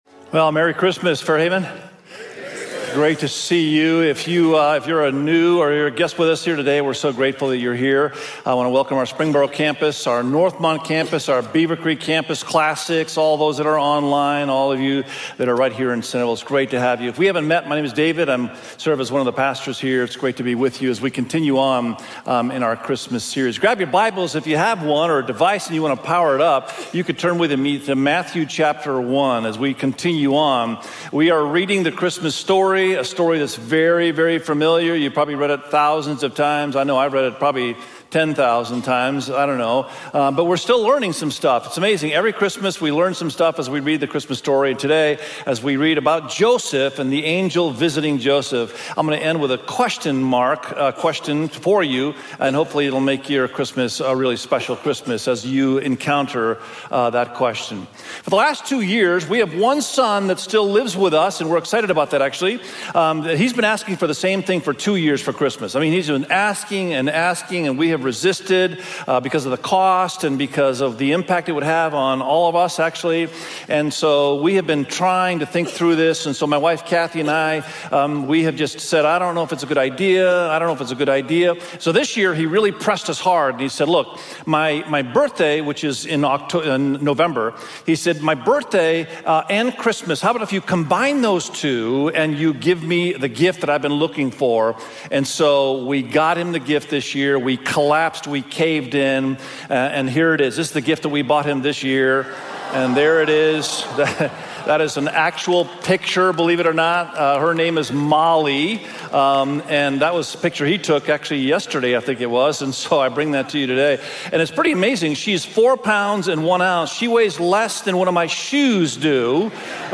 Unto-Us_God-With-Us_SERMON.mp3